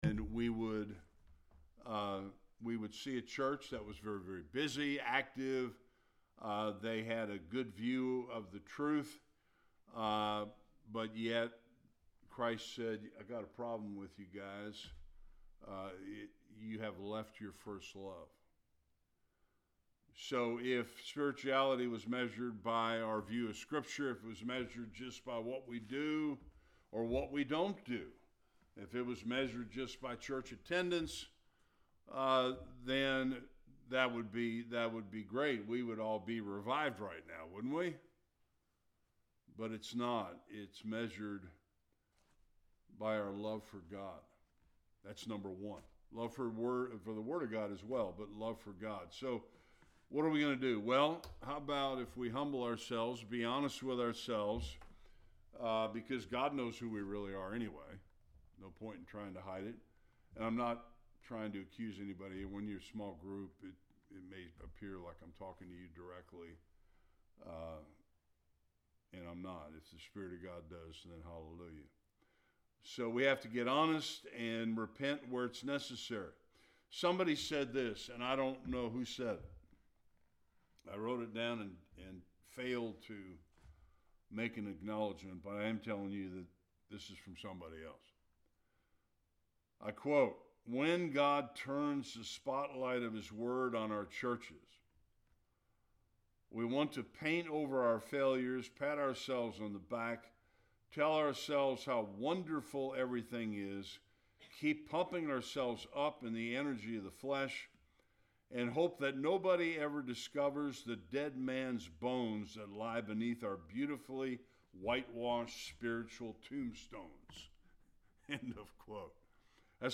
Various Passages Service Type: Bible Study We will look at some results of a true revival and what revival is not and what it is.